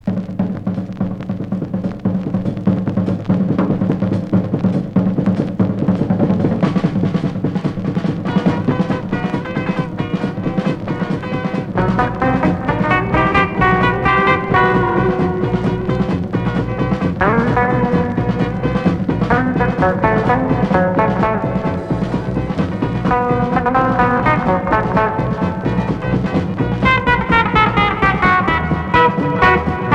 Rock instrumental Unique EP retour à l'accueil